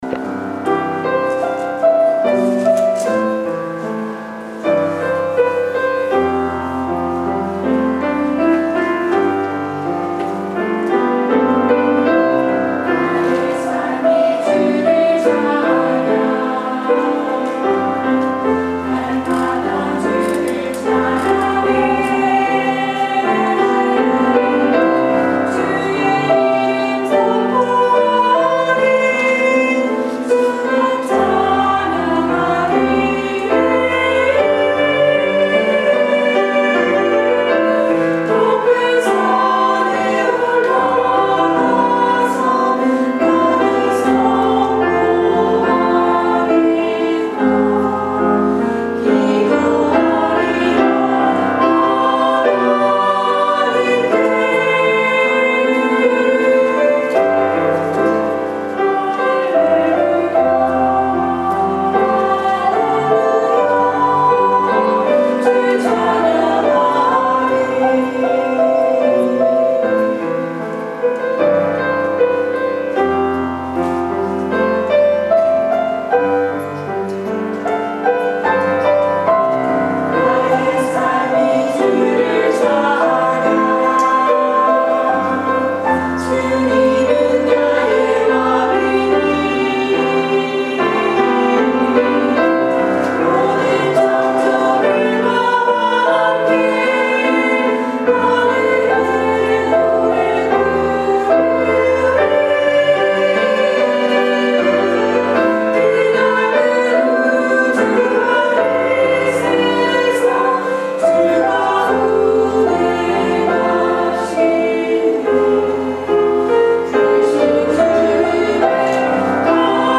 주일찬양